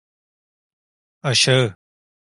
Uitgespreek as (IPA) /aʃaˈɯ/